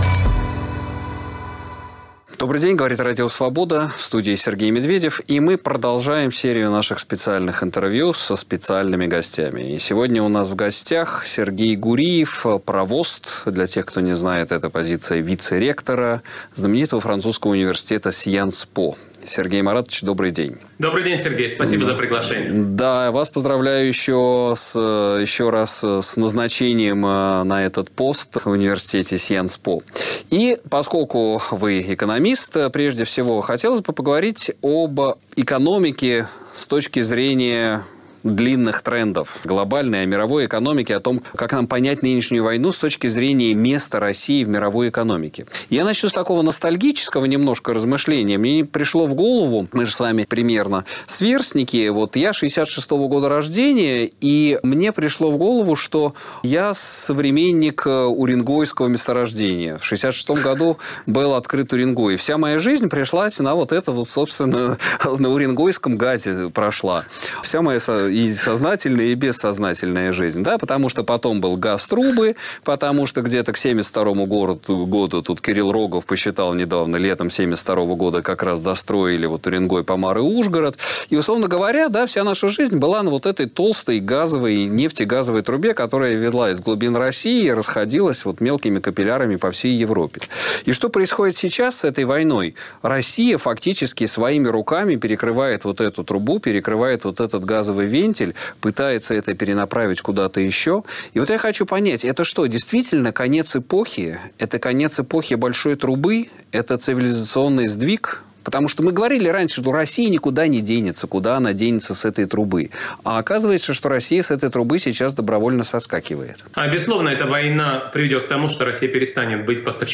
Беседа с известным экономистом, провостом университета Science Po